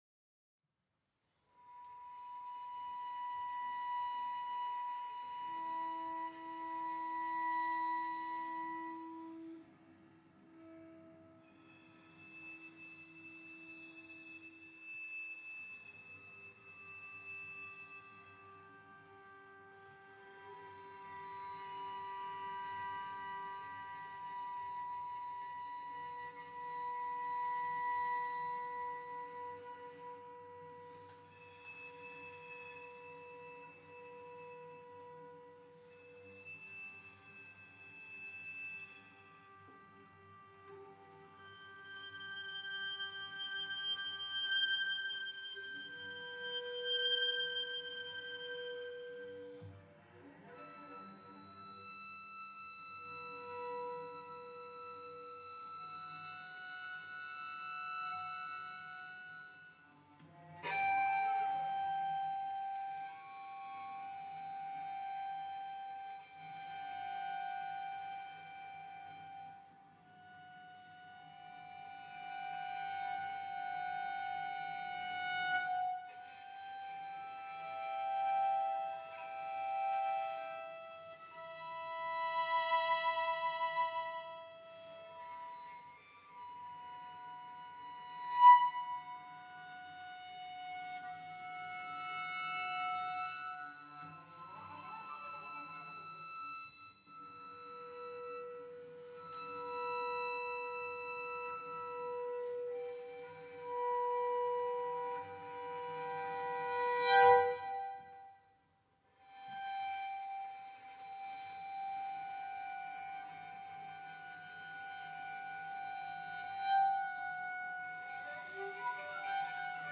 Venue: Bantry House
Instrumentation: vn, vc Instrumentation Category:Duo
violin
cello